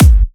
edm-kick-80.wav